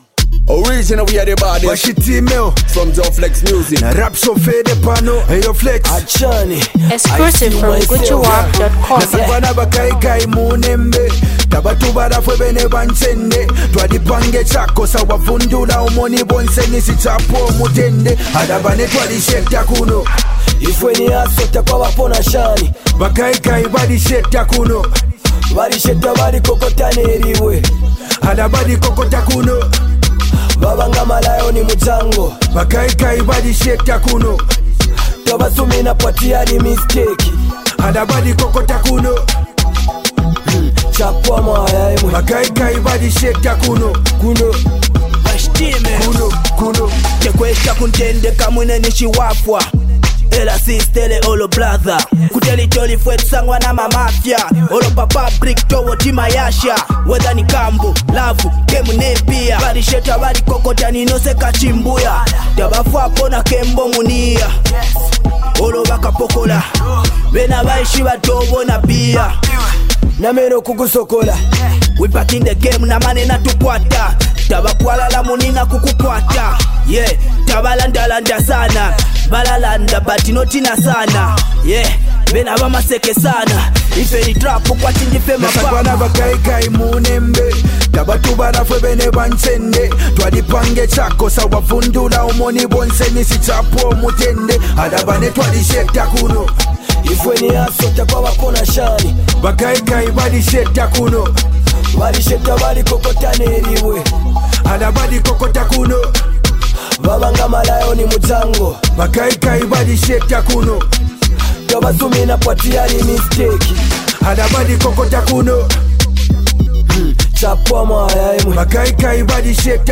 ghetto inspiration genre that Contains real ghetto-vibes
The master mind-blower of afro/dancehall beats.